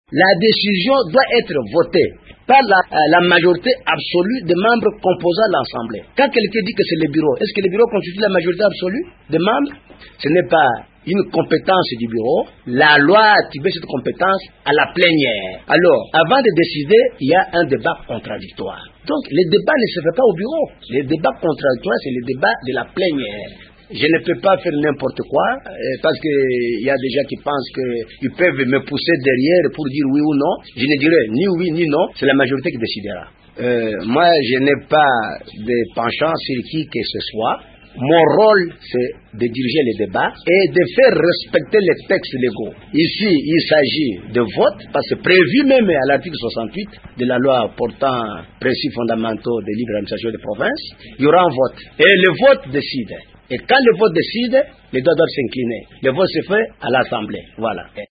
Selon le président  Omer  Mijimbu sha  Kalau, le réquisitoire doit être soumis à un débat contradictoire à l’assemblée provinciale: